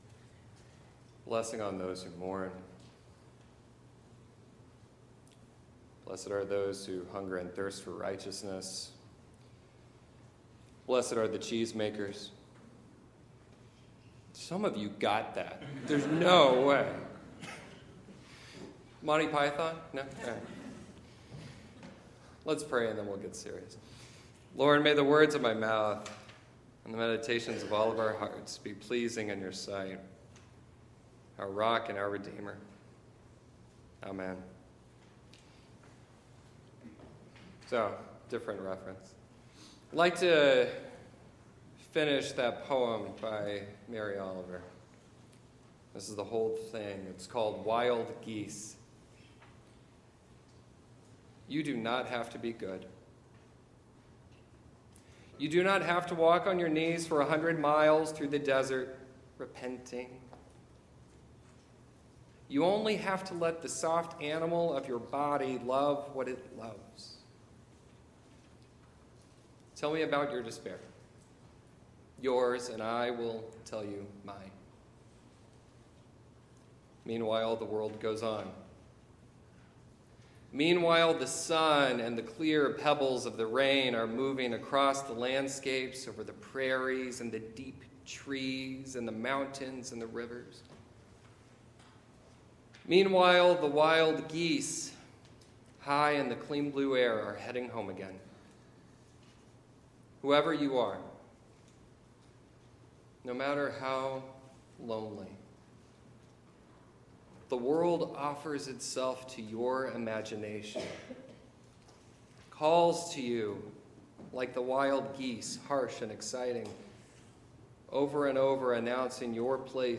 sermon-2019-01-27.mp3